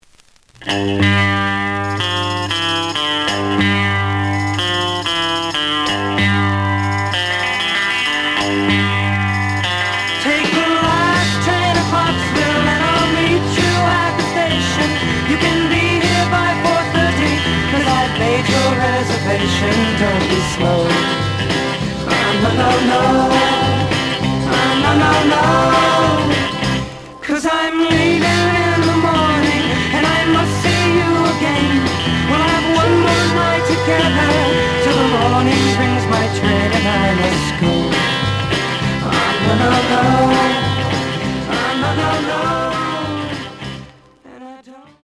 The audio sample is recorded from the actual item.
●Genre: Rock / Pop
Noticeable cloudy on both sides.